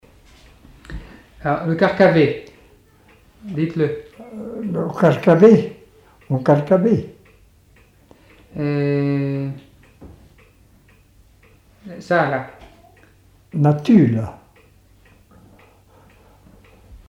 Appels d'animaux, locution vernaculaires, chansons et témoignages
Catégorie Locution